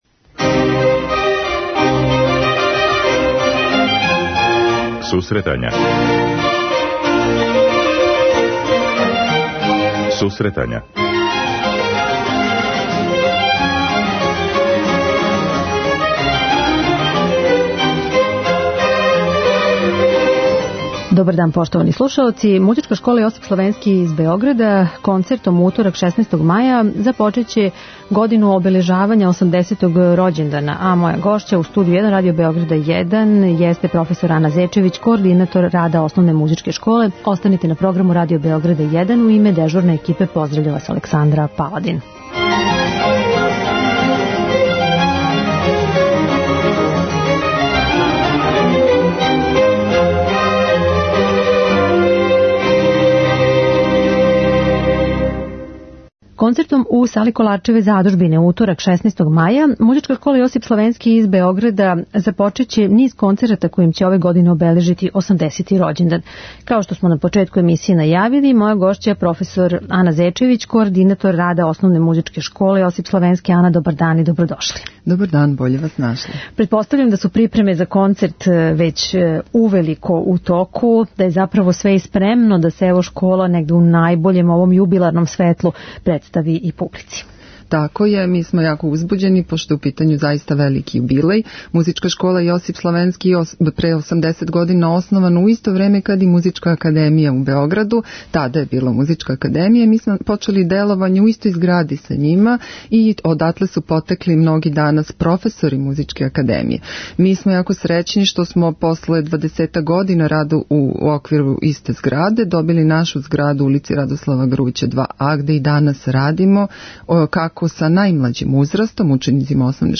преузми : 10.47 MB Сусретања Autor: Музичка редакција Емисија за оне који воле уметничку музику.